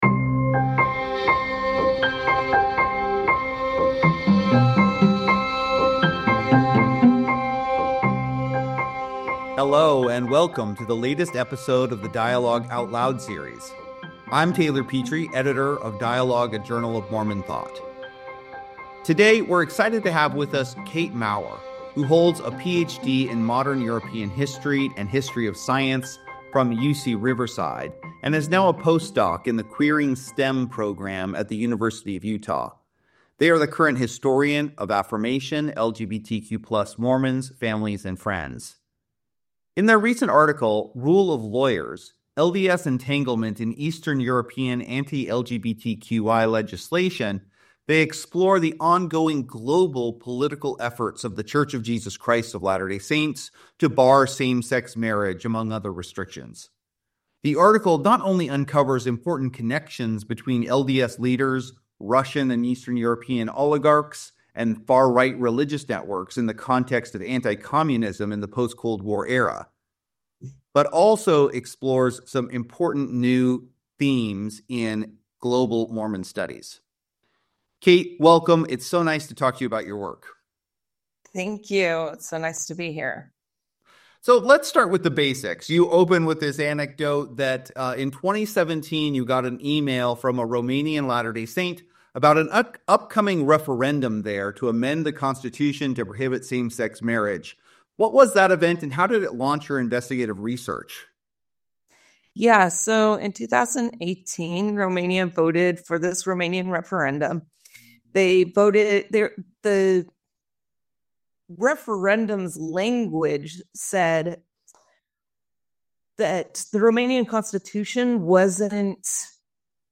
Connecting Queer Dots in Eastern Europe: A Conversation